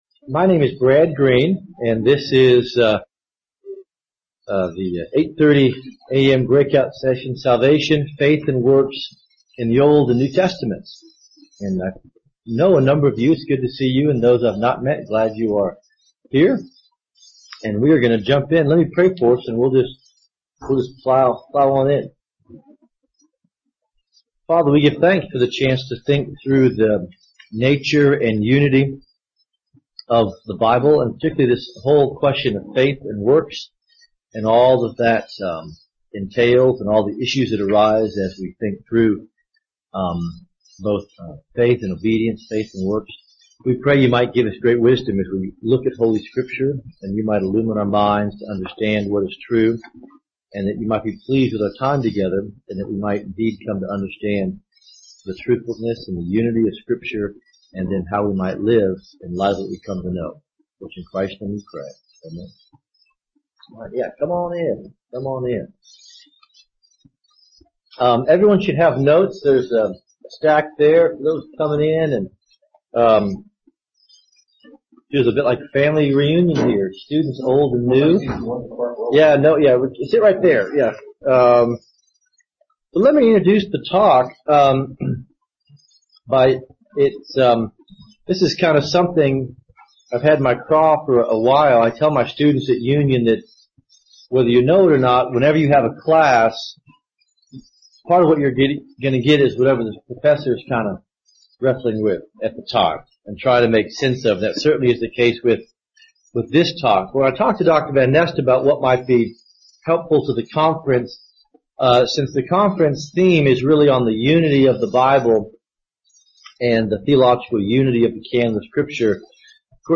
Address: Salvation: Faith & Works in the Old & New Testaments Recording Date